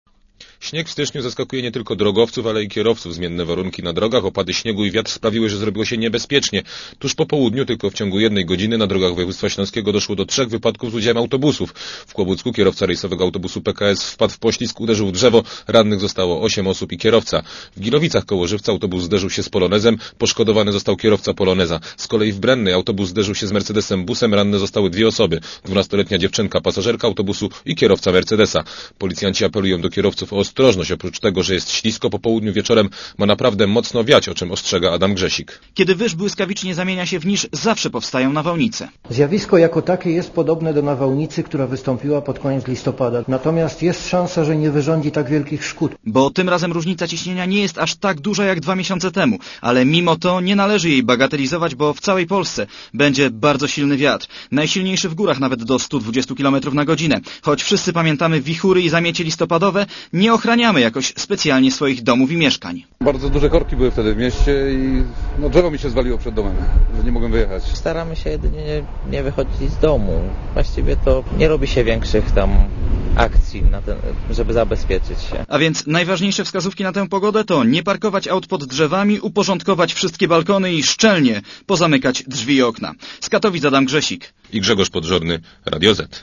Posłuchaj relacji reporterów Radia ZET